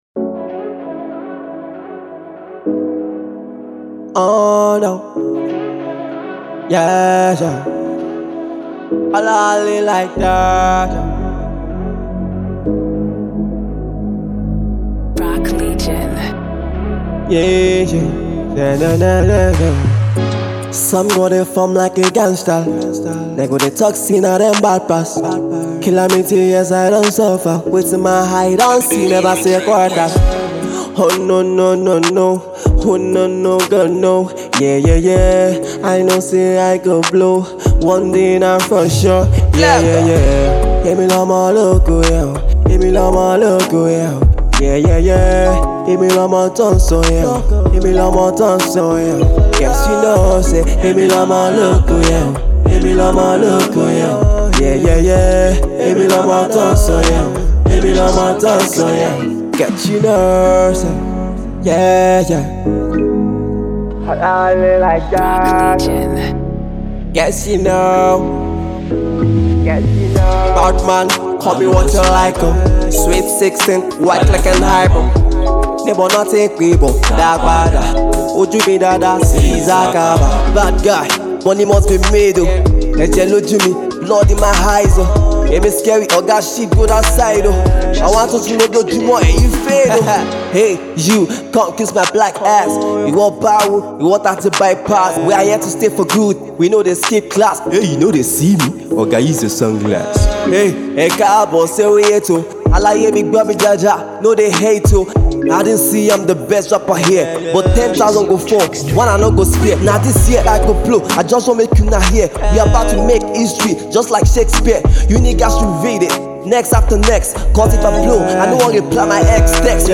Sensational Singer/Rapper